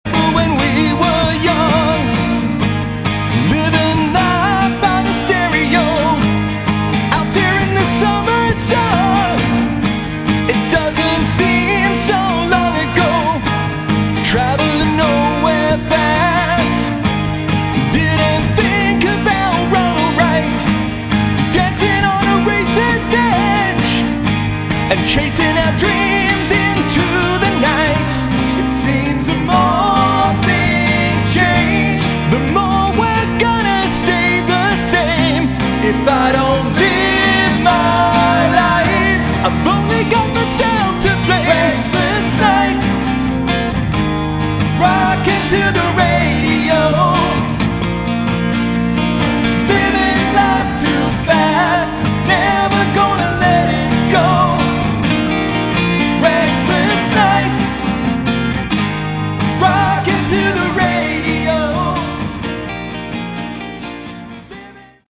Category: AOR
acoustic bonus track